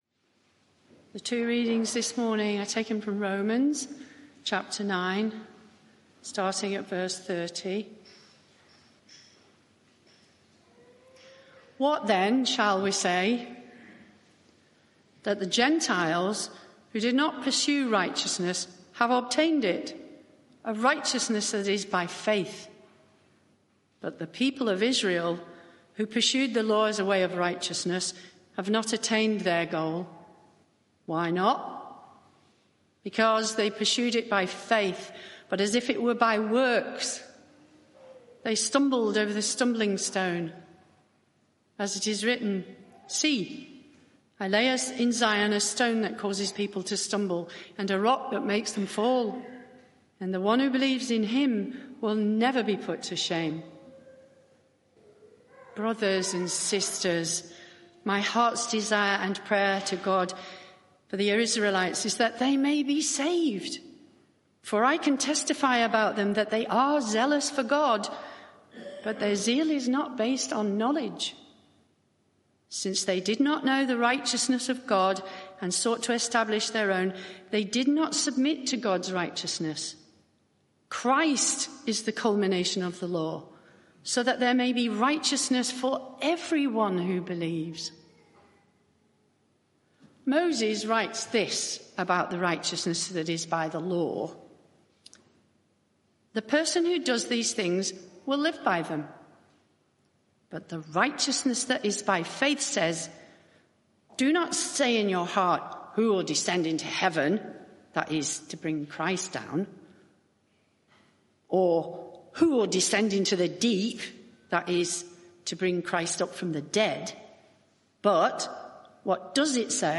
Media for 11am Service on Sun 06th Oct 2024 11:00 Speaker
Sermon (audio)